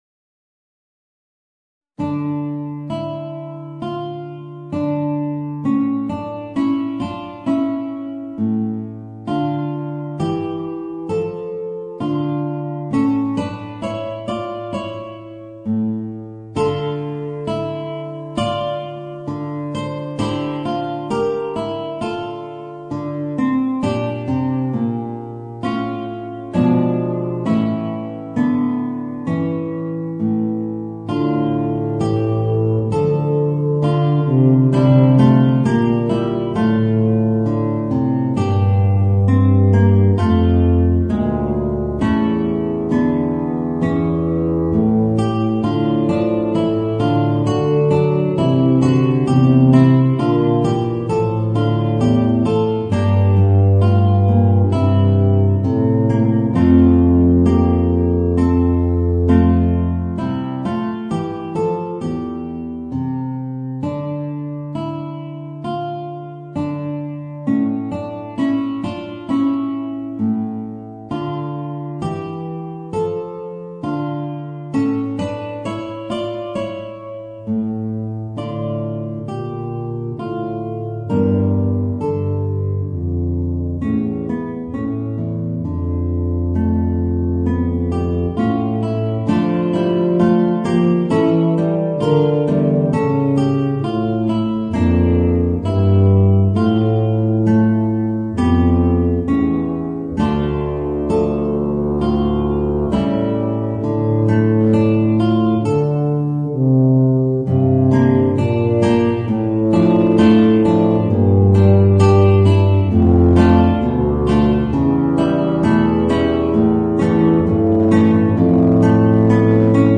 Voicing: Bb Bass and Guitar